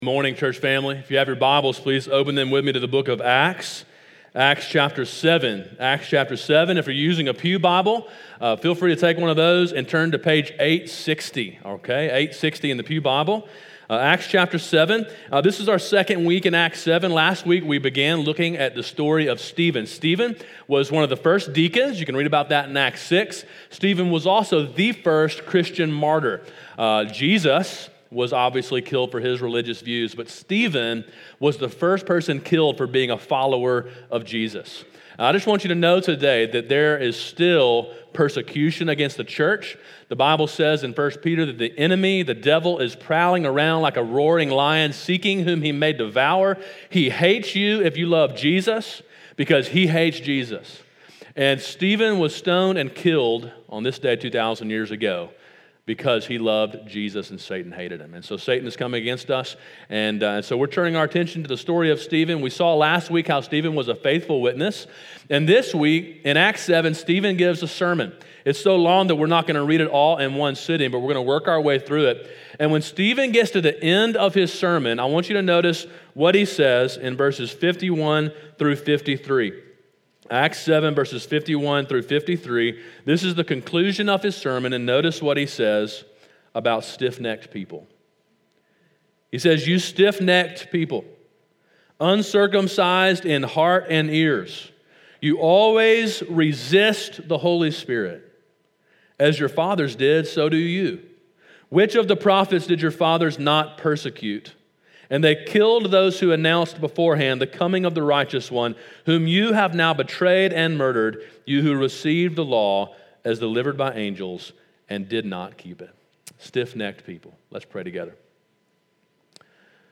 Sermon: “Stiff-Necked People” (Acts 7) – Calvary Baptist Church